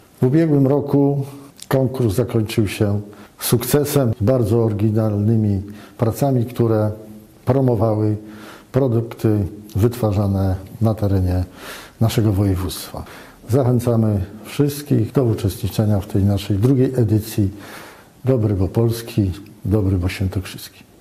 Mówi marszałek województwa Andrzej Bętkowski: